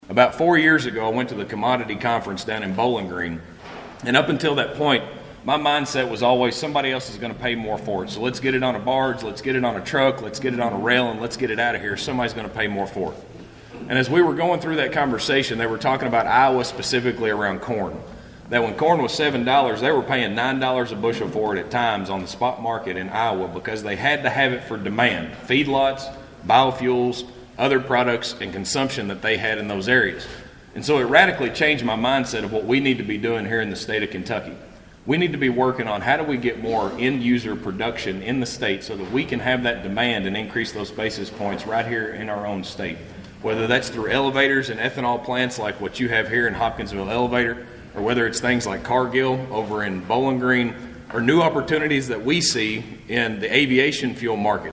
Agriculture Commissioner Shell Keynote Speaker At Christian County Salute To Agriculture Breakfast